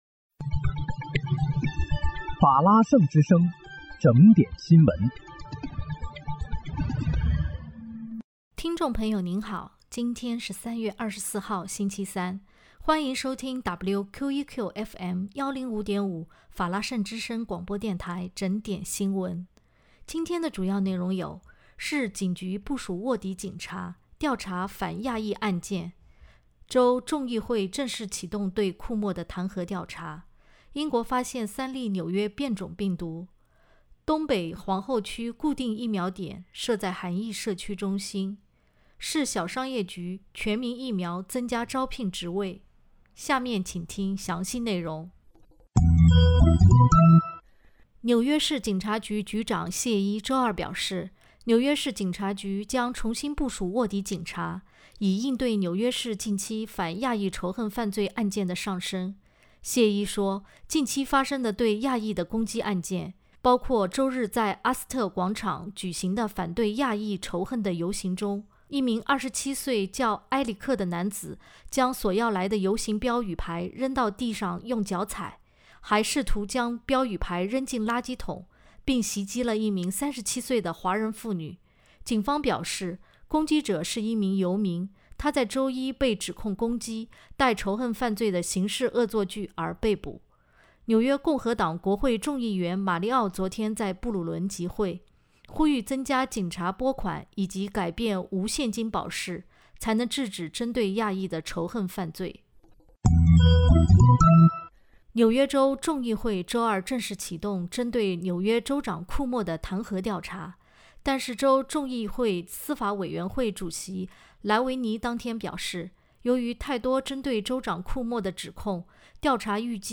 3月24日（星期三）纽约整点新闻